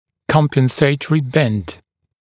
[ˌkɔmpən’seɪtərɪ bend][ˌкомпэн’сэйтэри бэнд]компенсаторный изгиб